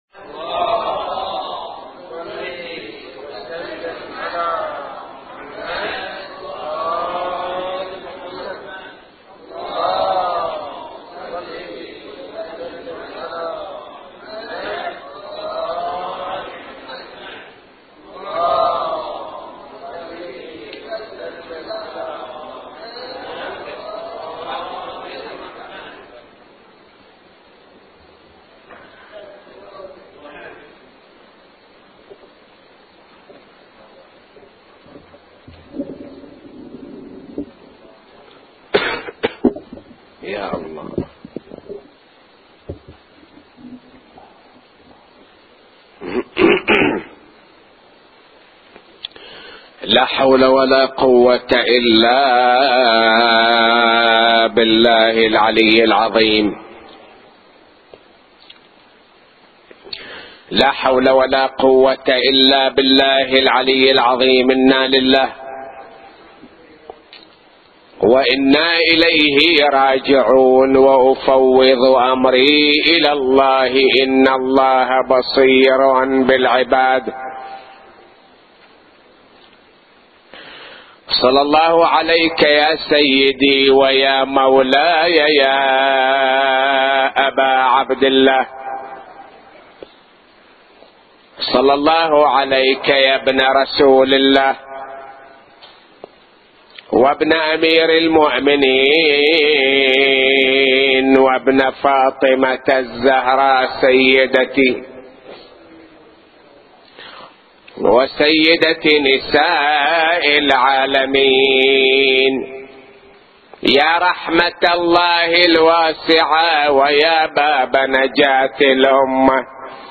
نواعي وأبيات حسينية – 8